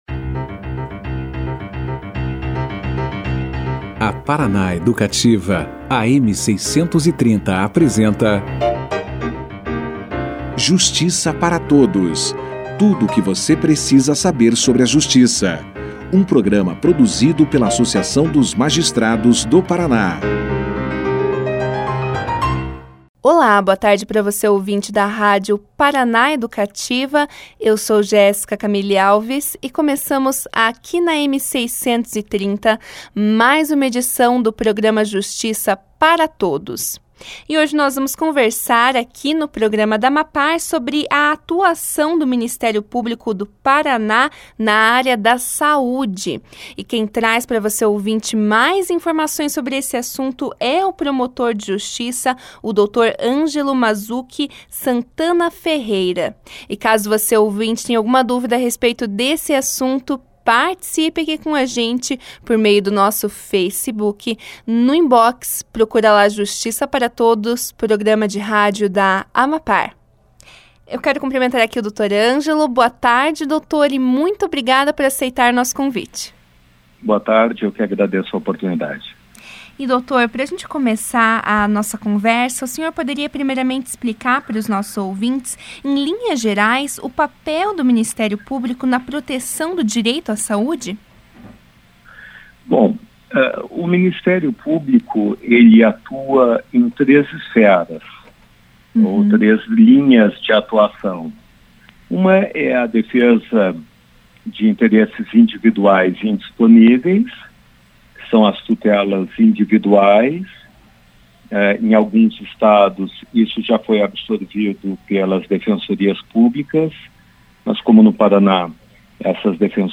O Promotor de justiça Angelo Mazzuchi Santana Ferreira conversou com o Justiça para Todos, nesta quinta-feira (10), sobre a atuação do Ministério Púbico do Paraná na área de saúde. O convidado deu início a entrevista esclarecendo o papel do MP PR em garantir o direito a saúde, de que forma o órgão atua na fiscalização da aplicação do orçamento público destinado ao SUS e os principais problemas existentes no Sistema único de Saúde atualmente.